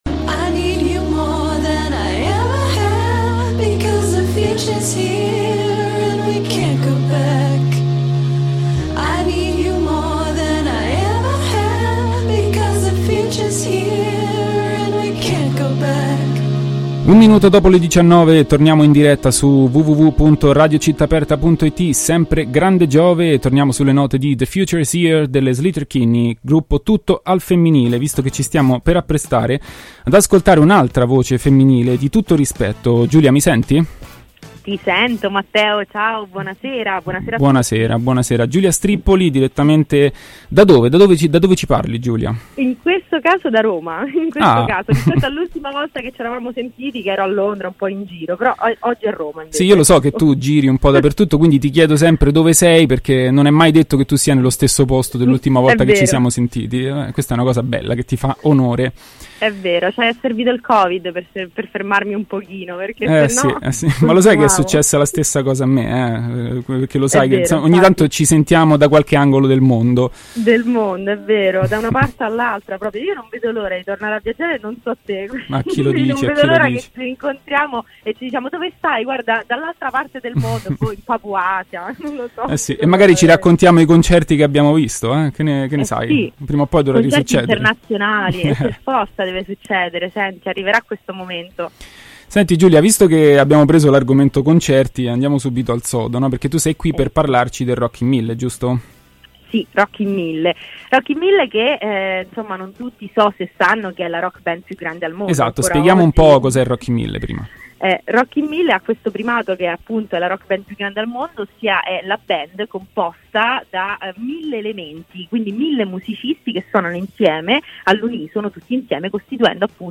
Intervista-rock1000.mp3